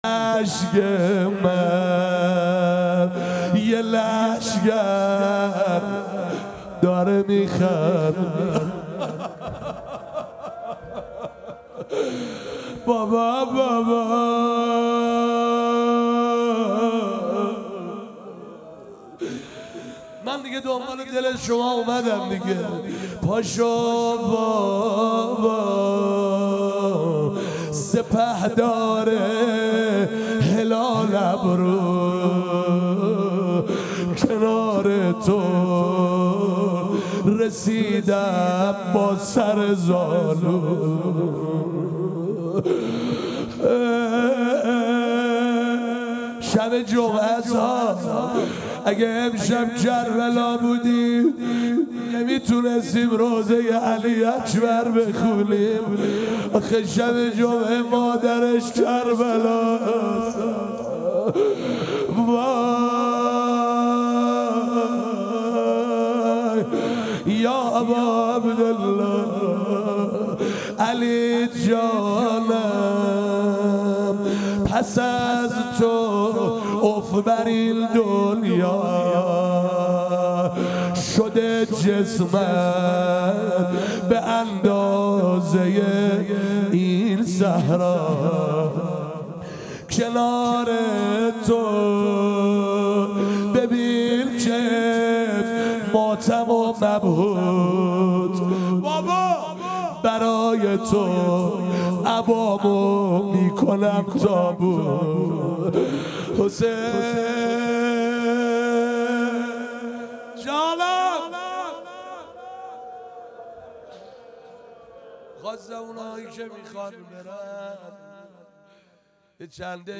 روشه شب هشتم